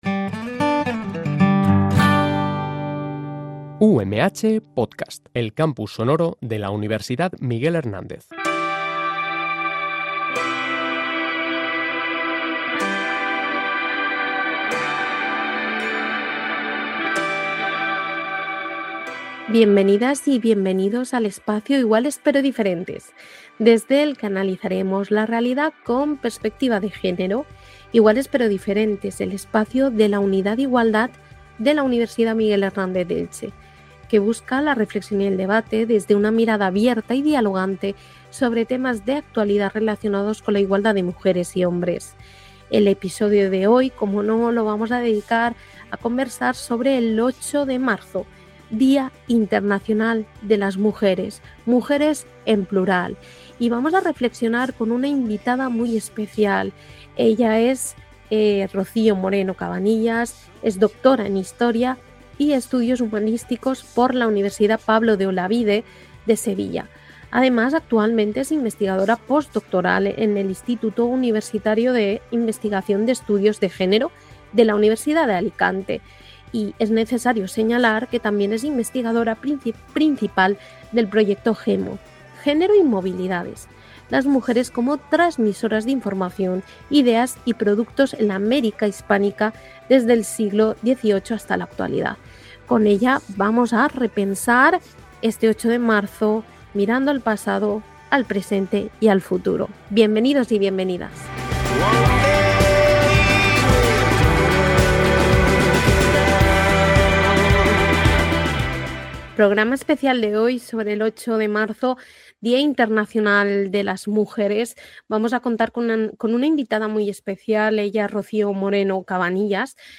En el episodio de hoy conversamos con